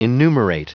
Prononciation du mot enumerate en anglais (fichier audio)
Prononciation du mot : enumerate